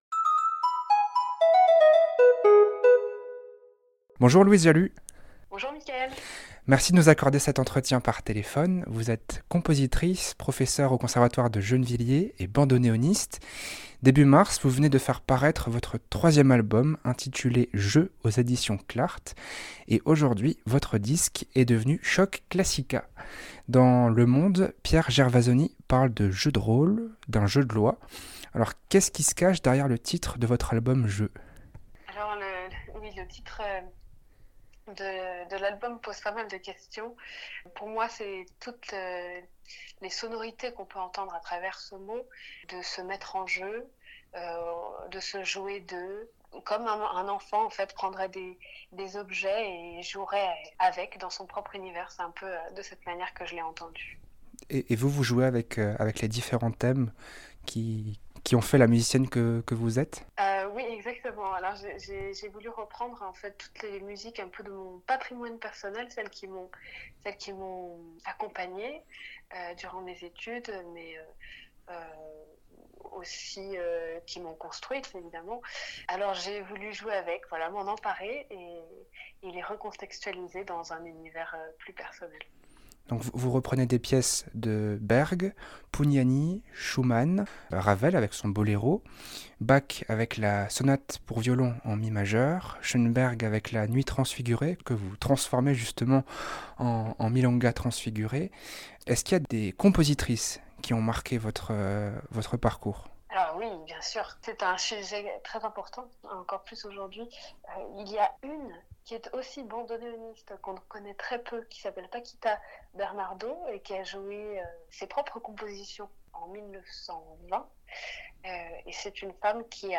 Interview-JEU-FINIE.mp3